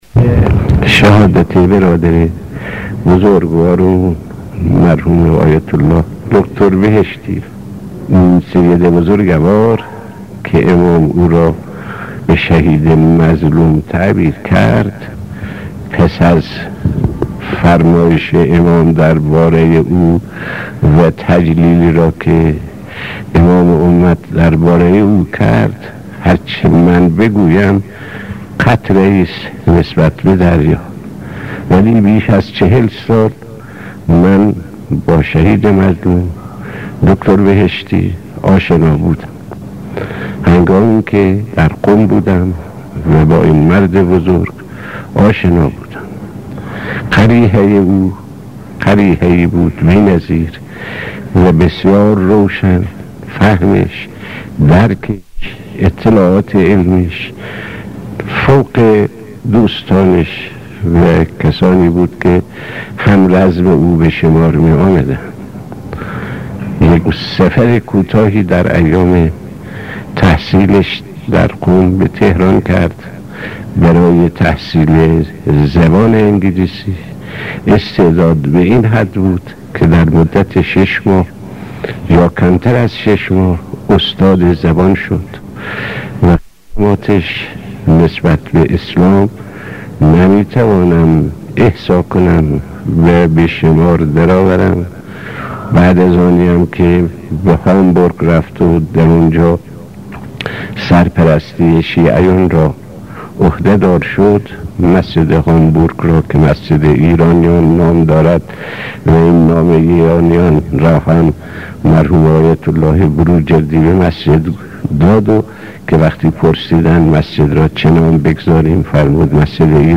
صوت/ سخناني از شهيد آيت الله صدوقى پيرامون شخصیت شهید بهشتی